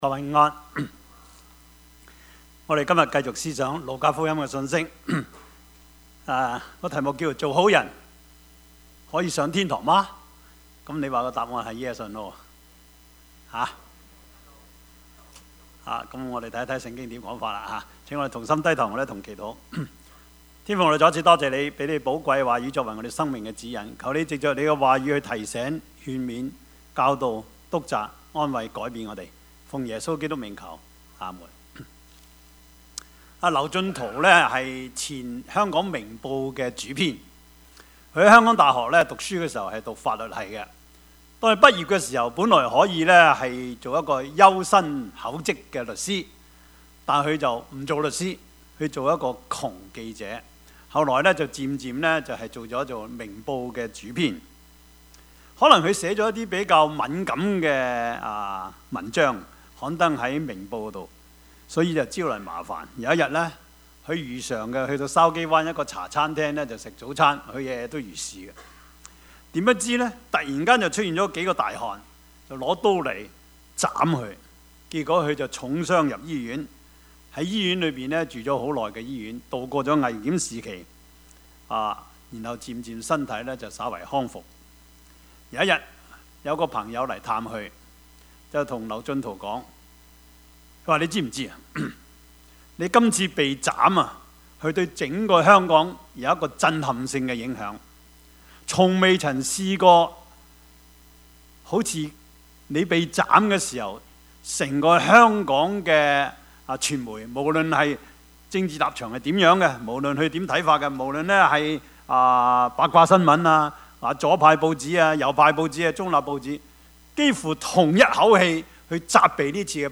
Service Type: 主日崇拜
Topics: 主日證道 « 你的王來了 苦難與榮耀 »